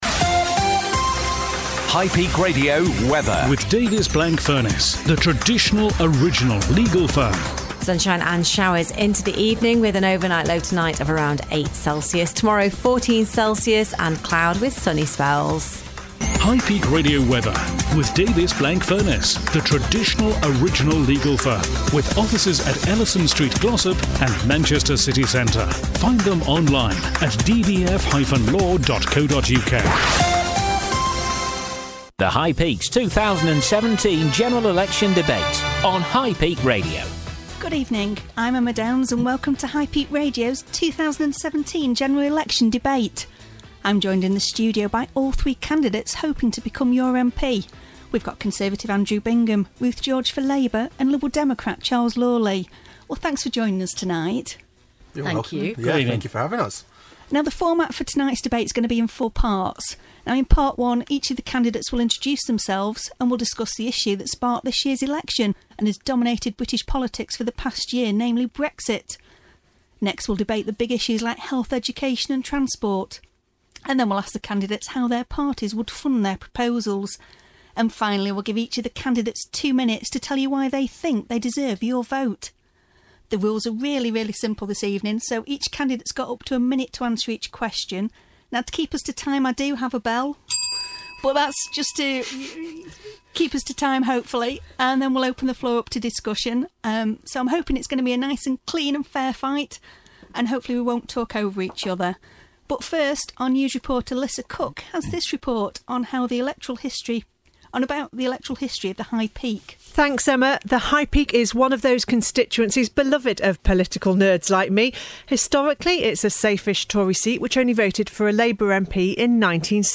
LISTEN AGAIN: High Peak Radio General Election 2017 Debate Pt 1